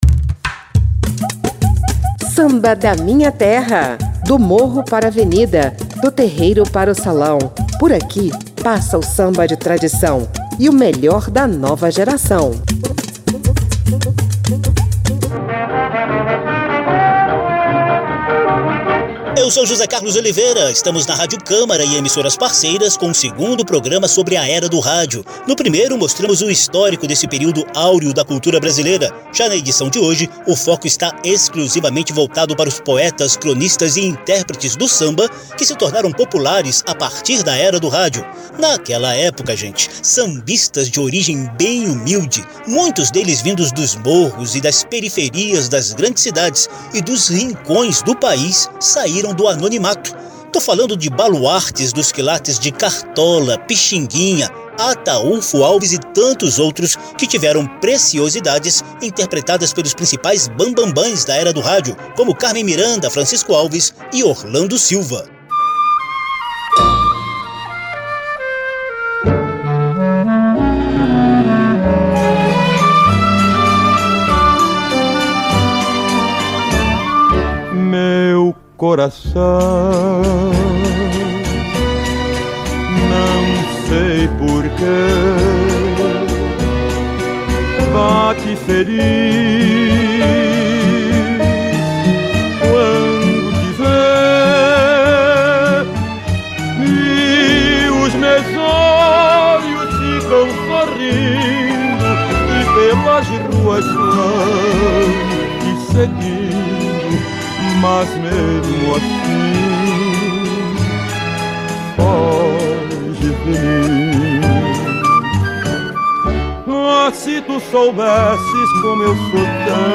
Samba da Minha Terra resgata essa relevância da “era do rádio” para o samba por meio de depoimentos de Elza Soares, Adoniran Barbosa, Dorival Caymmi, Elizeth Cardoso, Ismael Silva e Moreira da Silva,